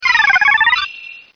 P3D-Legacy / P3D / Content / Sounds / Cries / 433.wav